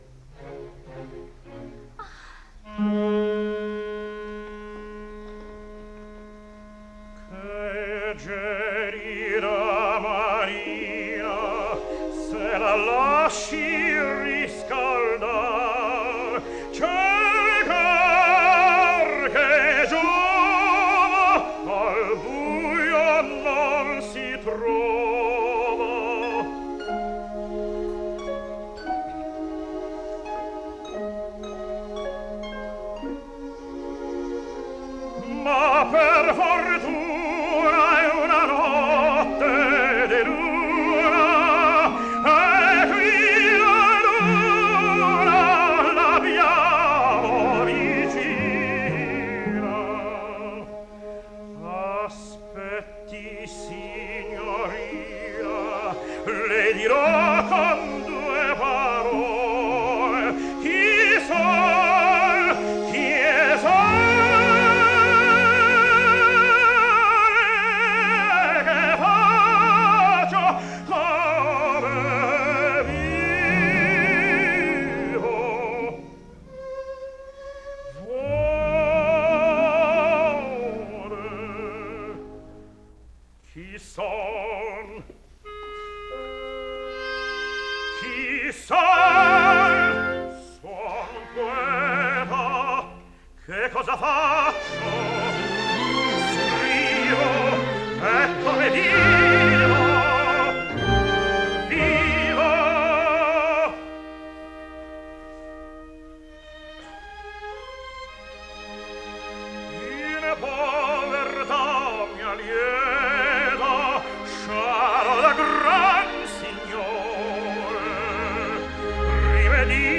10 - Puccini. La Boheme - Che gelida manina (Rodolfo - Jan Peerce) (1946)